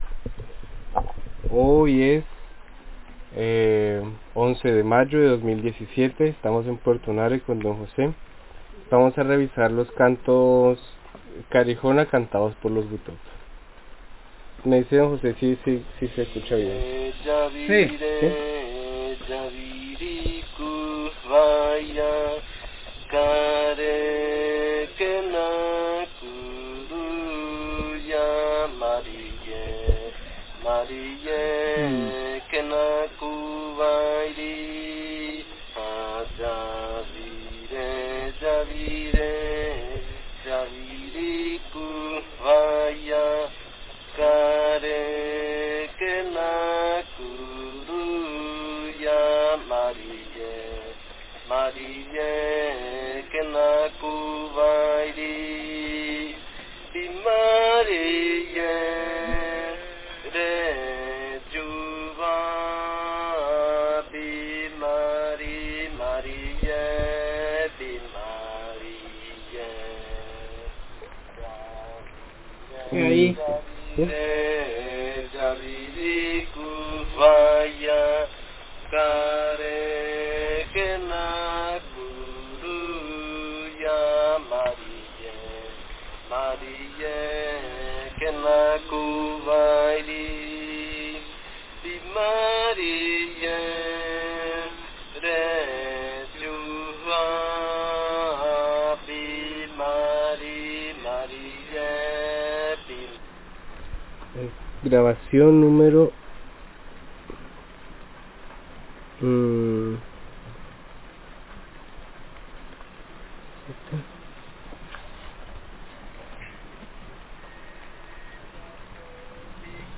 This recording is part of the collection of mythis, stories and songs collected by the Karijona Linguistics Seedbed (Department of Linguistics, Faculty of Human Sciences, Bogotá campus of UNAL) collected from the Karijona people in the Caquetá and Vaupés Rivers between 1985 and 2021 .